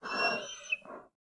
Minecraft Version Minecraft Version snapshot Latest Release | Latest Snapshot snapshot / assets / minecraft / sounds / mob / panda / worried / worried2.ogg Compare With Compare With Latest Release | Latest Snapshot
worried2.ogg